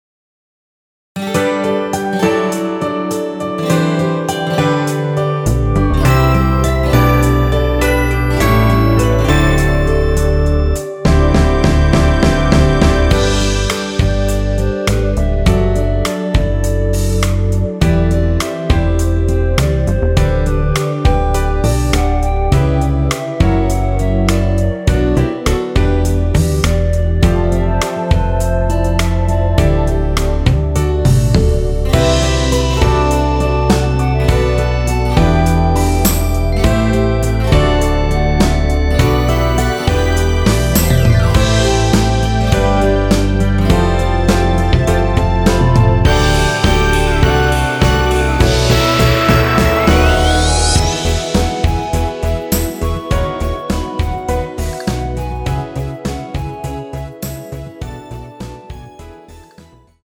원키에서(+5)올린 멜로디 포함된 MR입니다.(미리듣기 확인)
앞부분30초, 뒷부분30초씩 편집해서 올려 드리고 있습니다.
중간에 음이 끈어지고 다시 나오는 이유는